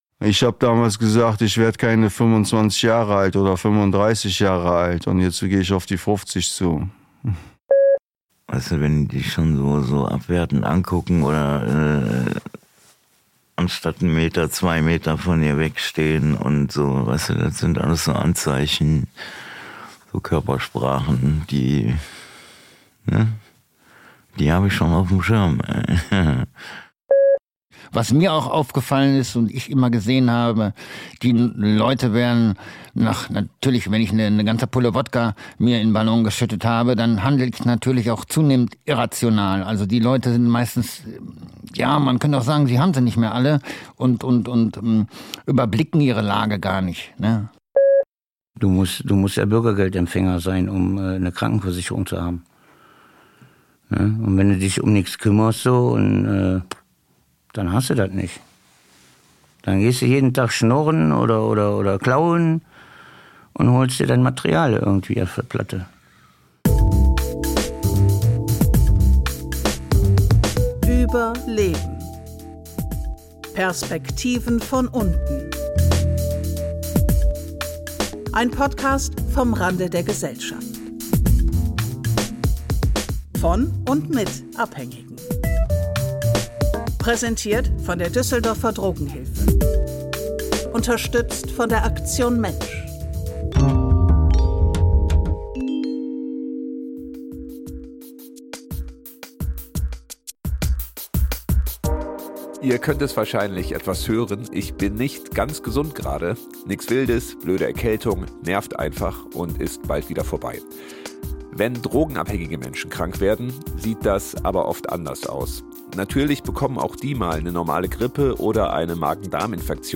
In dieser Folge berichten Abhängige über den Zusammenhang zwischen Drogenkonsum und Gesundheit. Sie erzählen von der Ungleichbehandlung im Krankenhaus und was passiert, wenn man im Gefängnis einen Arzt braucht.